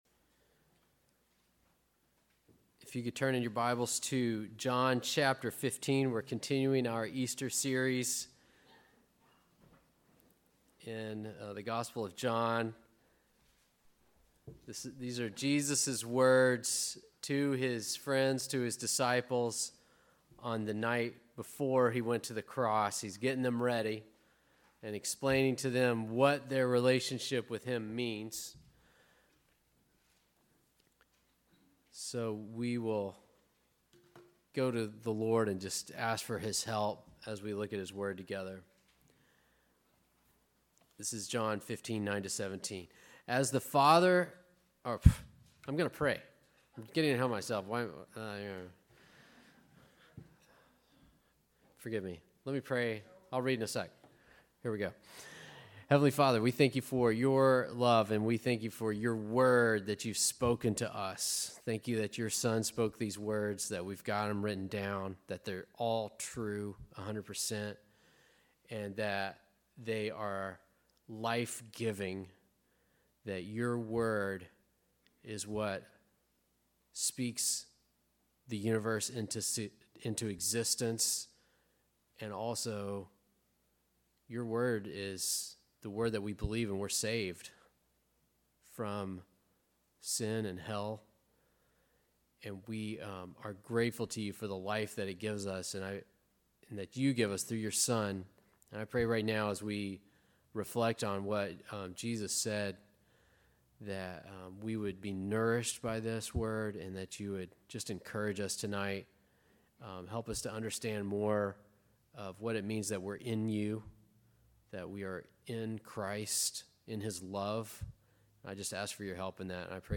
Listen to sermons by our pastor on various topics.
Good Friday Service